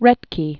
(rĕtkē, -kə, rĕth-), Theodore 1908-1963.